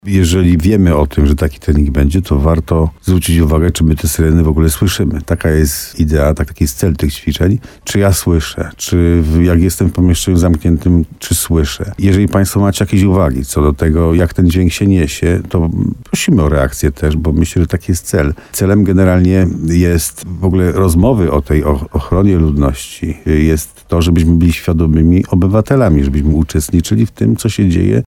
Tu dźwięków alarmowych powinniśmy się spodziewać między 11.00 a 12.00. Posłuchaj całej rozmowy z wójtem gminy Dobra: Tagi: test systemu Słowo za Słowo Limanowa Dobra test gmina Dobra Benedykt Węgrzyn alarm syrena